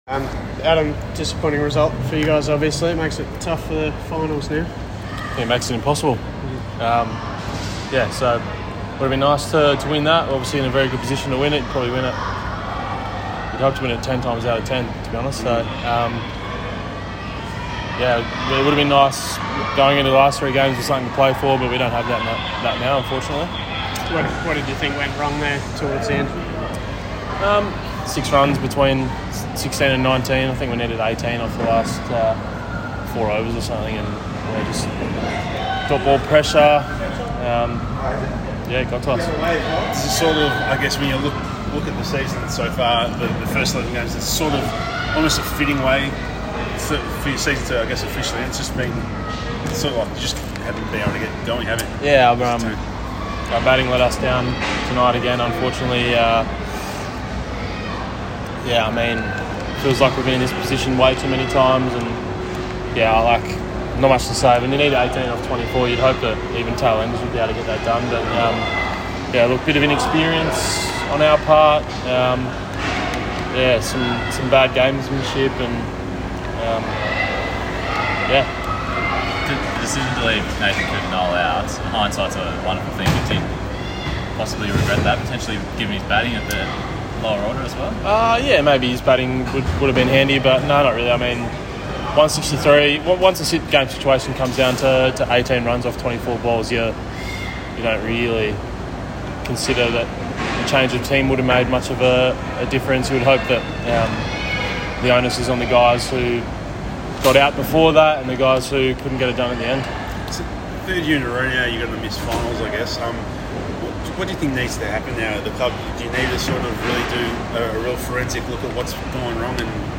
Adam Zampa speaks post match after the Stars lose to the Renegades by 6 runs